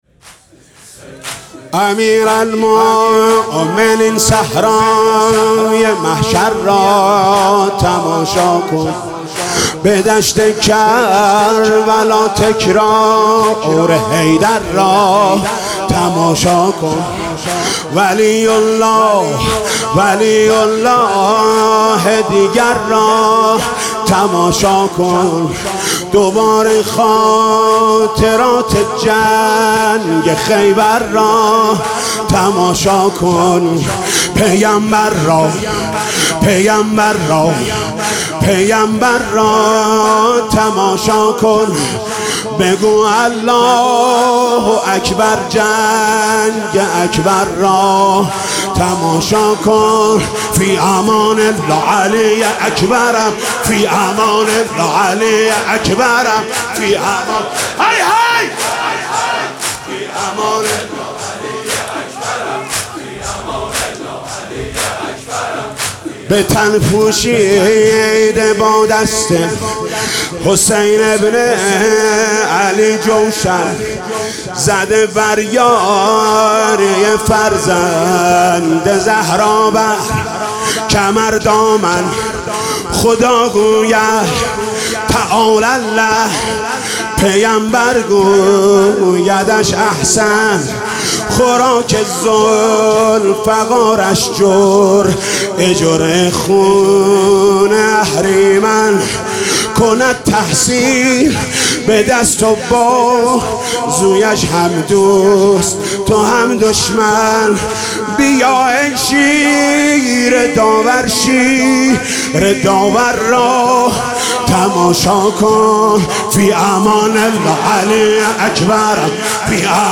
«محرم 1396» (شب هشتم) تک: امیرالمومنین صحرای محشر را تماشا کن
«محرم 1396» (شب هشتم) تک: امیرالمومنین صحرای محشر را تماشا کن خطیب: حاج محمود کریمی مدت زمان: 00:05:01